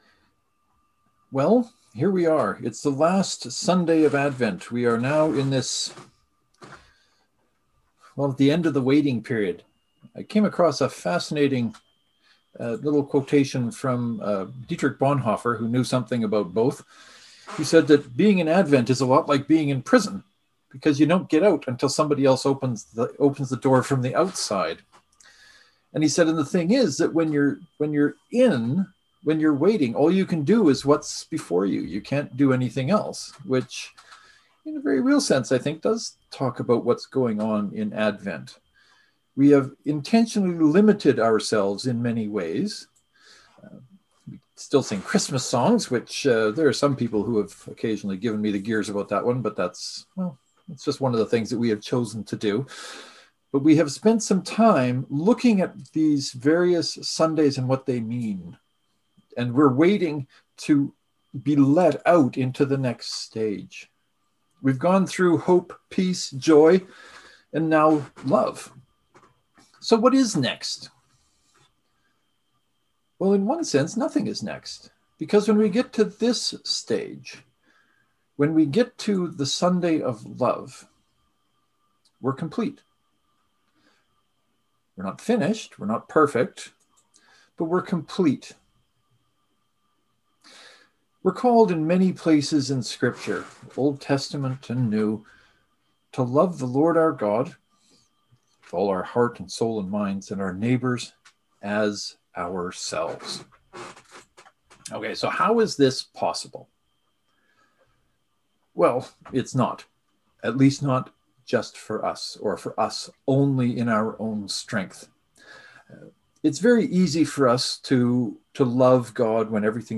Knox and St. Mark’s Presbyterian joint service (to download, right click and select “Save Link As .